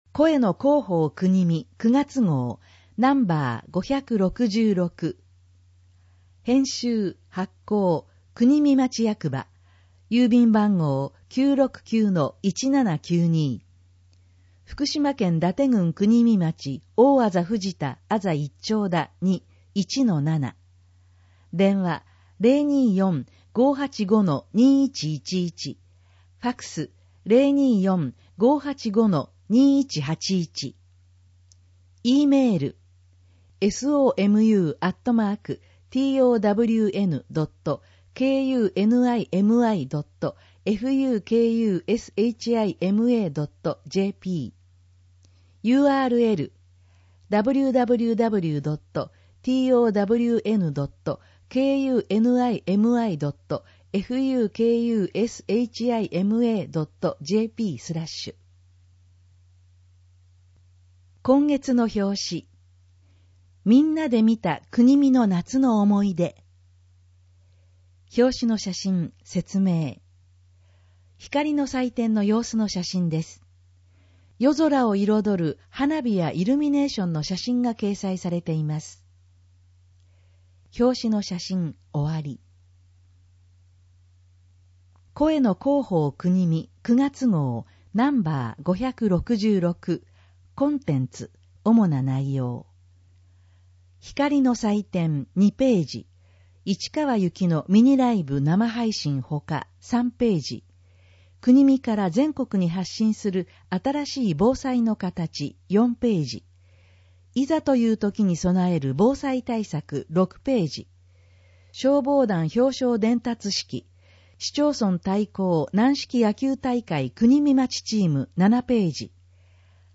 ＜外部リンク＞ 声の広報 広報紙の内容を音声で提供しています。